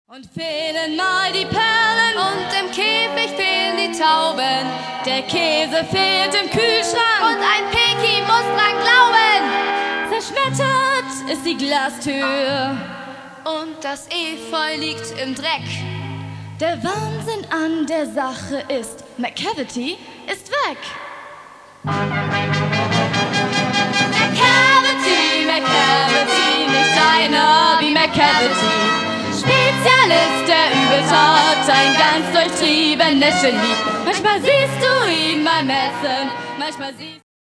Musical-Highlights in Concert Freudiana